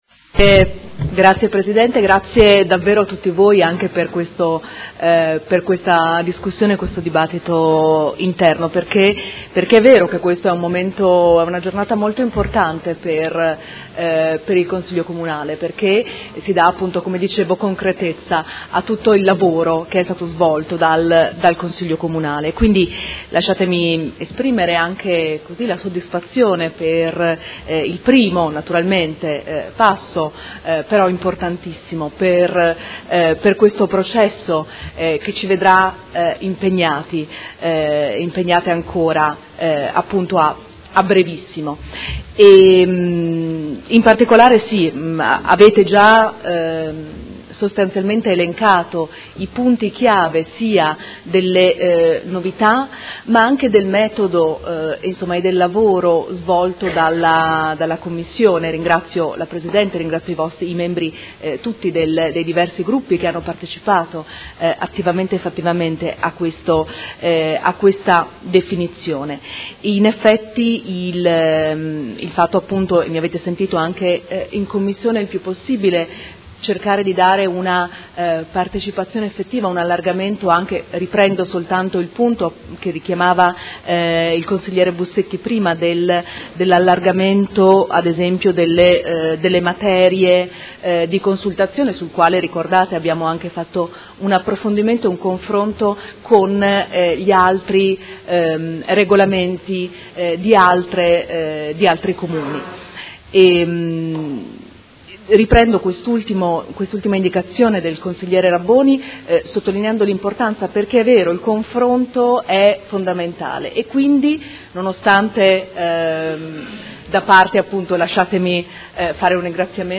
Seduta del 15/03/2018. Replica a dibattito su proposta di deliberazione: Revisione del Regolamento sugli istituti di partecipazione dei cittadini del Comune di Modena